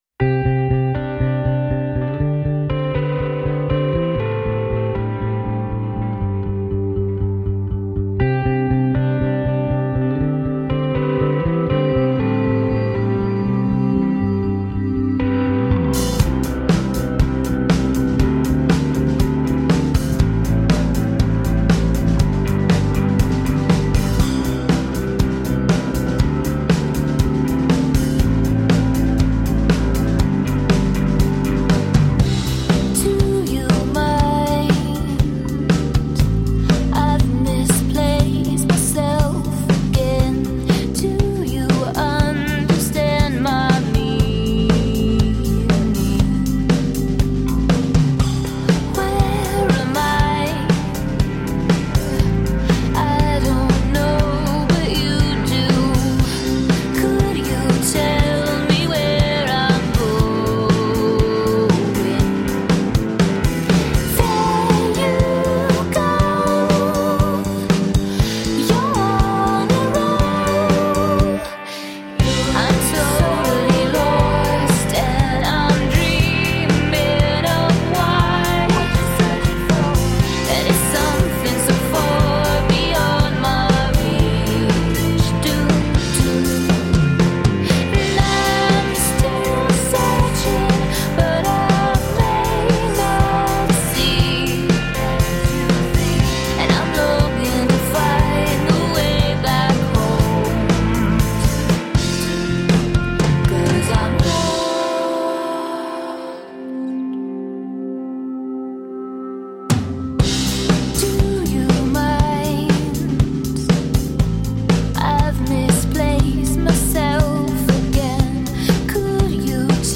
Pretty pop that sounds like jewel-tone colors.